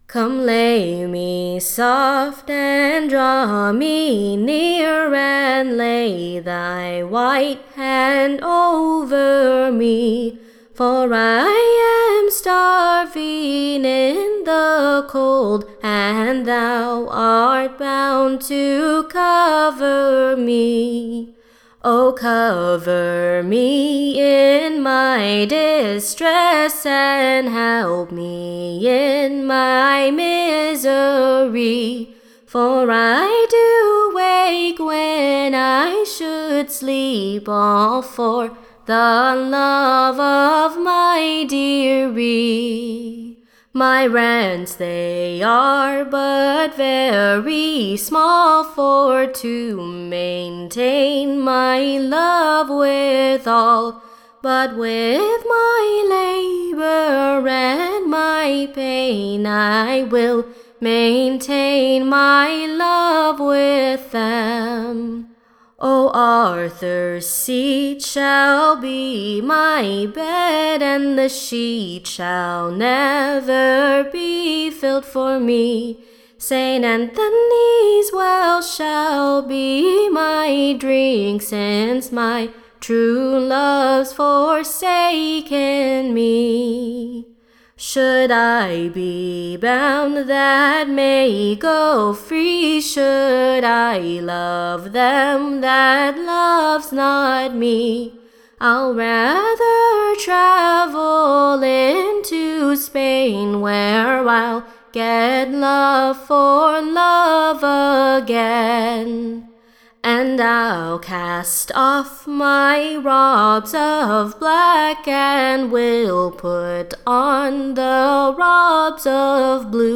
Recording Information Ballad Title ARTHUR'S-SEAT, / Shall be my BED, &c. / OR, / LOVE IN DESPAIR / A New Song much in Request, Tune Imprint Sung with its own proper Tune.